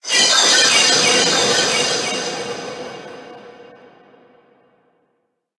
Cri d'Astronelle Gigamax dans Pokémon HOME.
Cri_0826_Gigamax_HOME.ogg